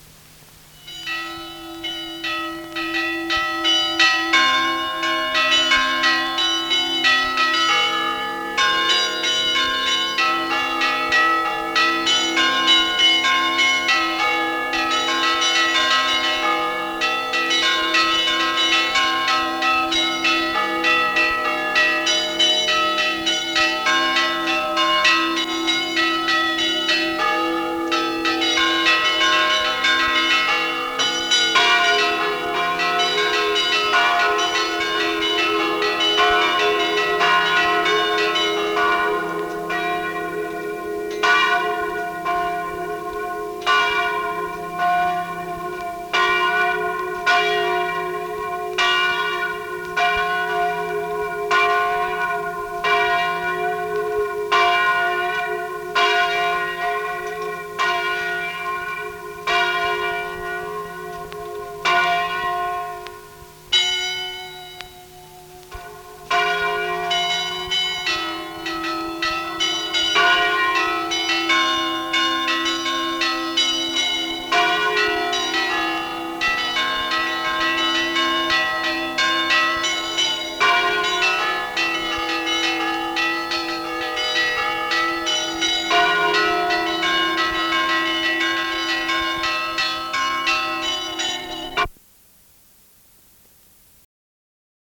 Sonneries de coches
Lieu : Mas-Cabardès
Genre : paysage sonore
Instrument de musique : cloche d'église